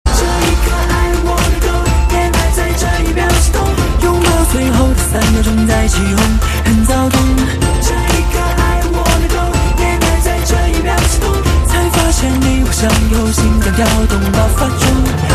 华语歌曲